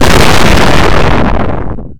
explode3.ogg